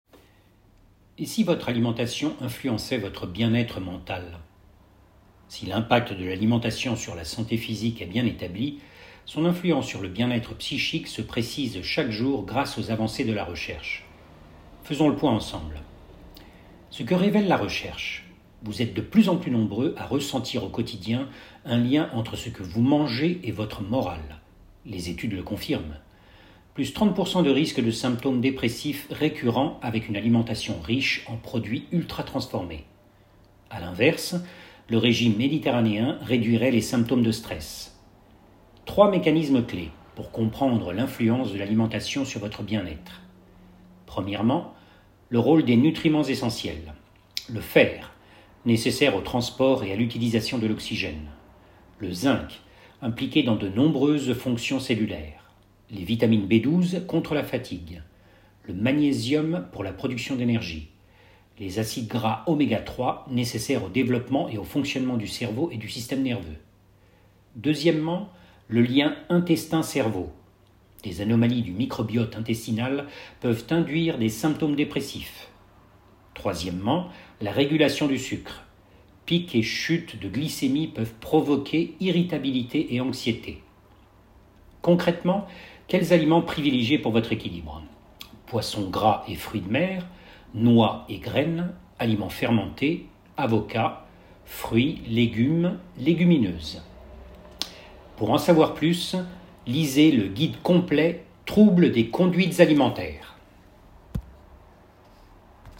Bandes-son
- Ténor